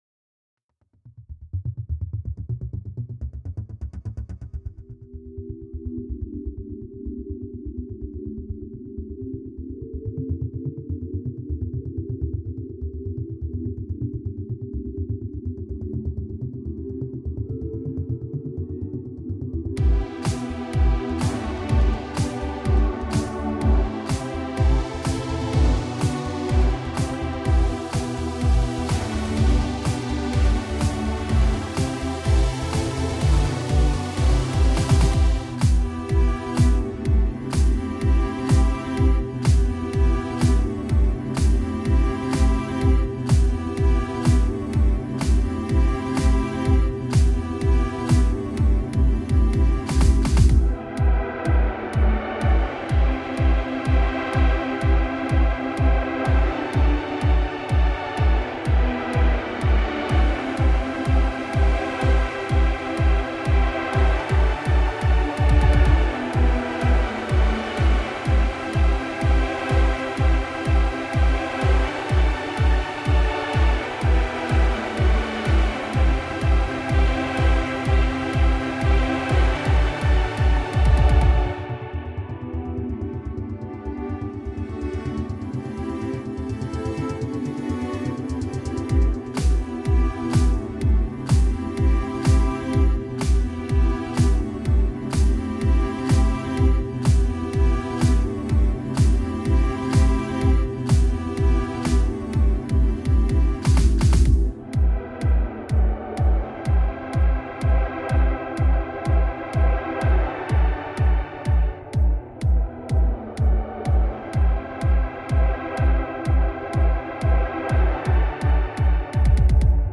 la bande-son de ce chapitre